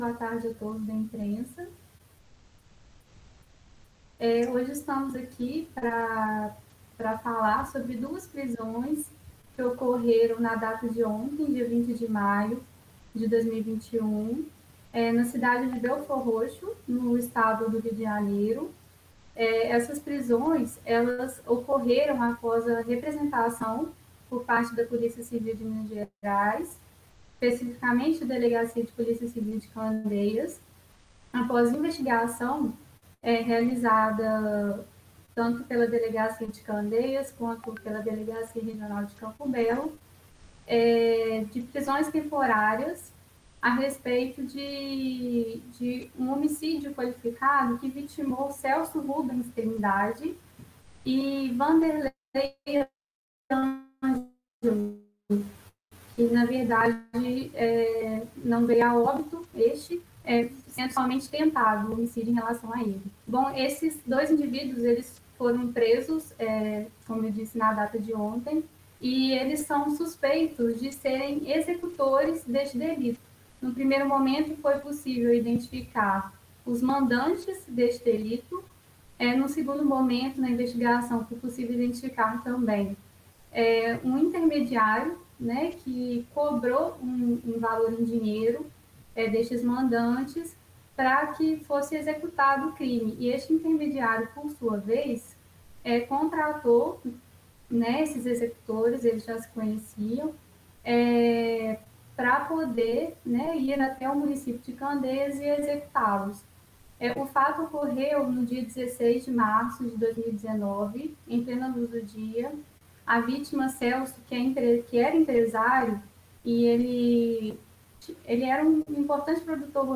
Coletiva-Policia-Civil.mp3